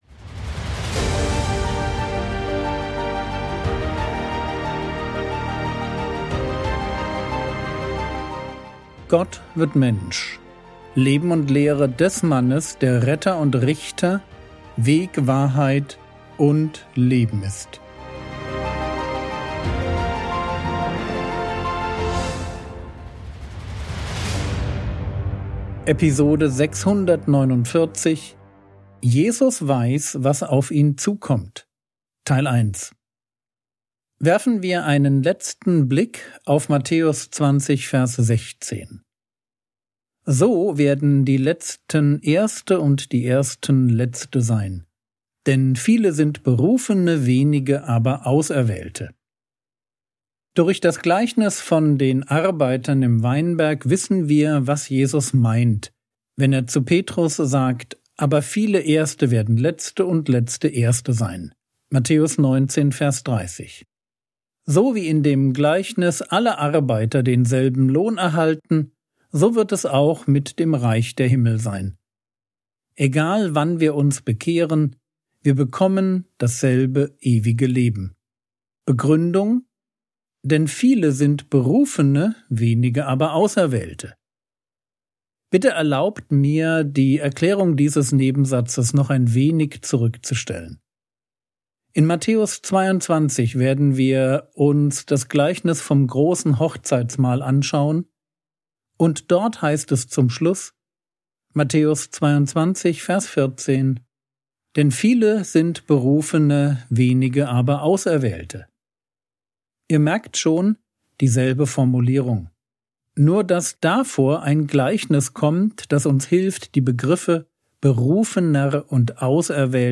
Episode 649 | Jesu Leben und Lehre ~ Frogwords Mini-Predigt Podcast